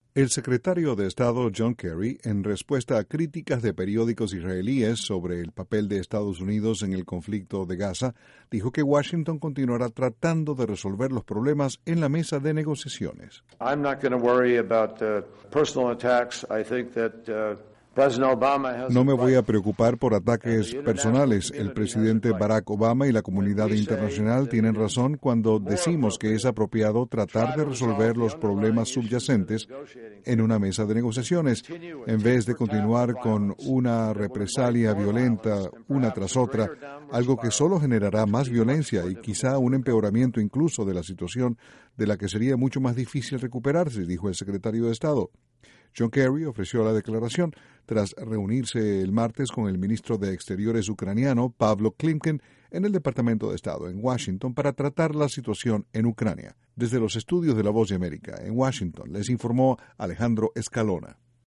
INTRO El Secretario Kerry respondió a editoriales israelíes según los cuales él arruinó la posibilidad de un cese el fuego en Gaza. Desde la Voz de América en Washington informa